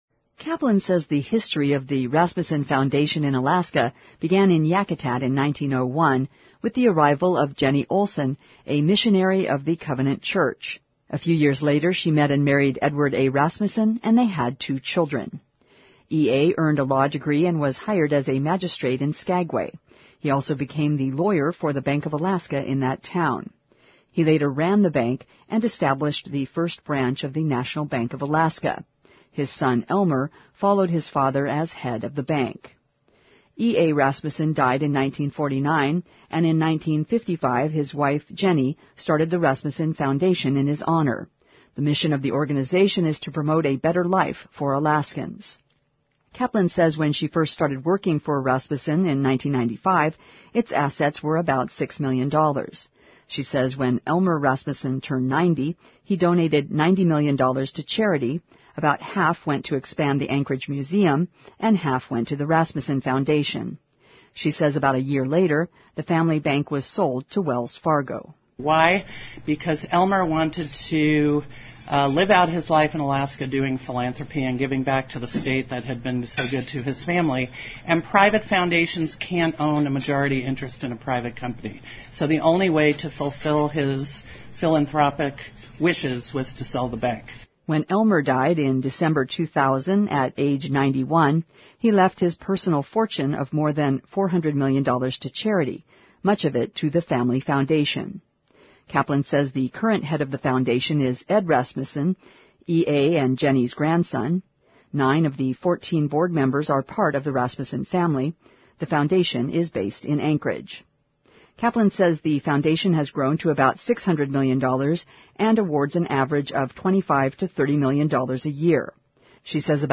Did you appreciate this report?